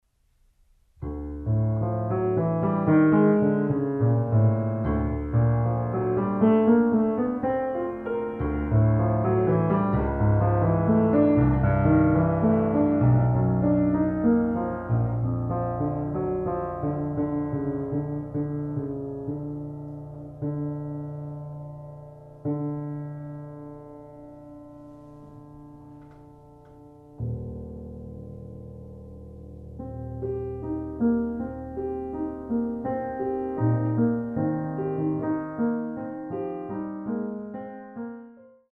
for Piano